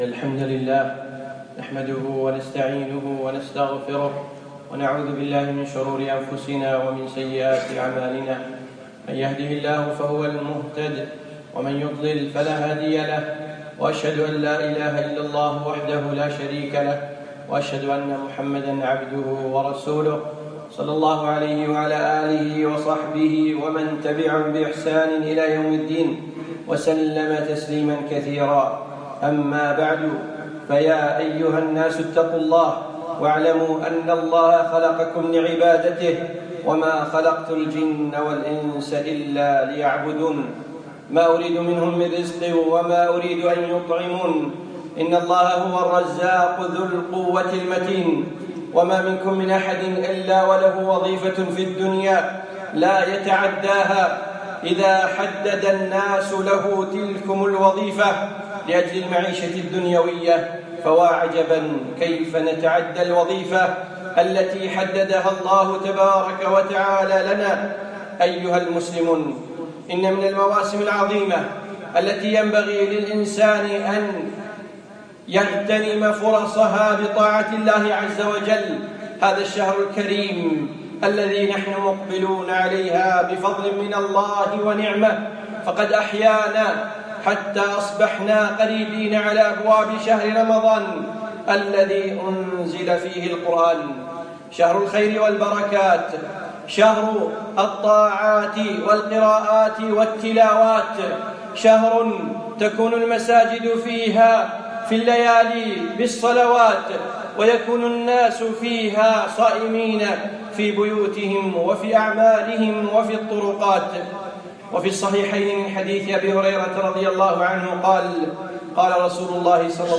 يوم الجمعة 27 شعبان 1437هـ الموافق 3 6 2016م في مسجد أحمد العجيل القصور